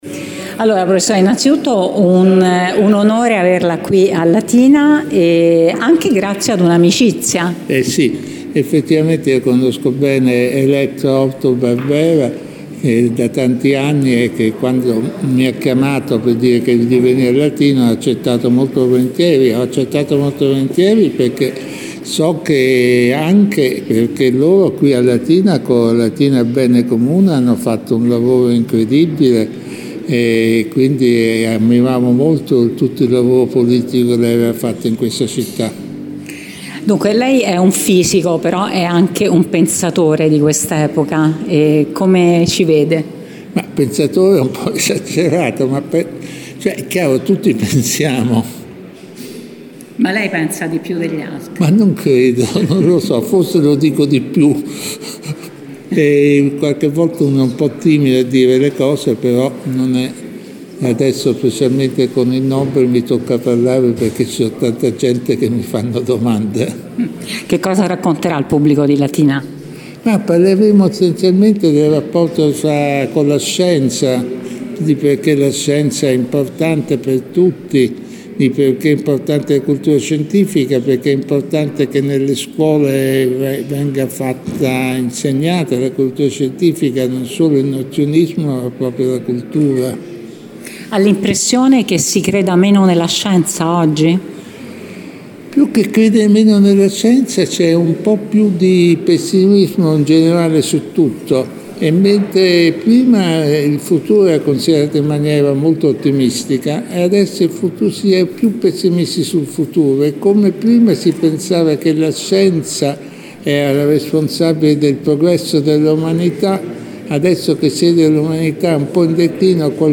Prima dell’inizio dell’appuntamento abbiamo scambiato due chiacchiere con lo scienziato